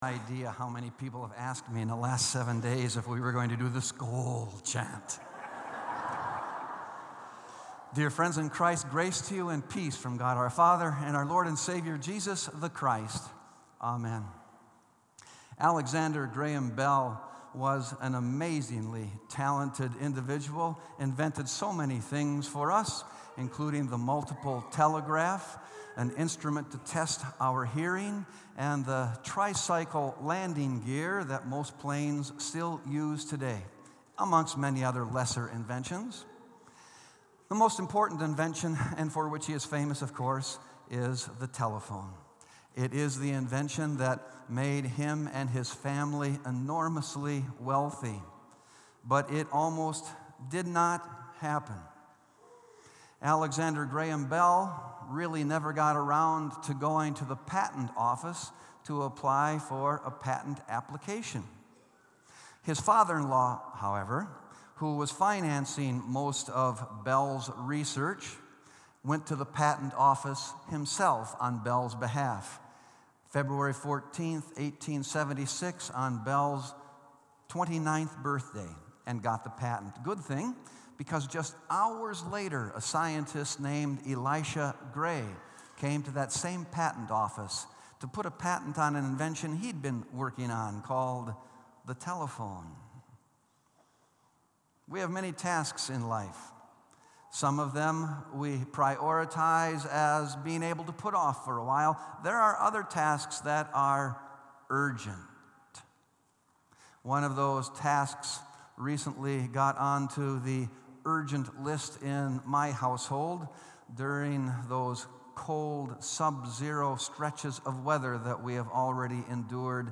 Sermon “The Time Is Now” | Bethel Lutheran Church